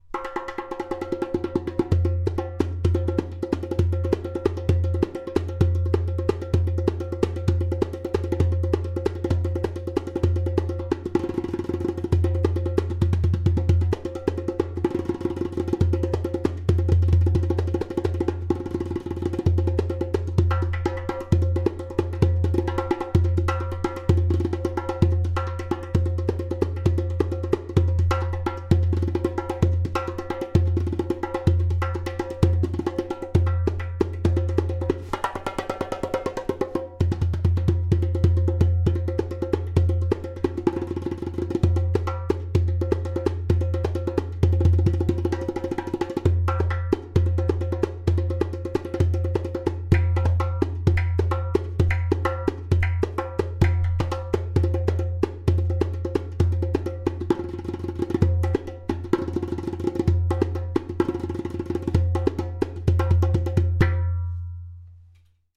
90bpm
115bpm
130bpm
In this line of darbukas materials like clay, glaze and natural skin met in a magical way which brings into life a balanced harmonic sound.
• Strong and easy to produce clay kik (click) sound
• Even tonality around edges.
• Beautiful harmonic overtones.
• Medium thickness goat skin (0.3mm)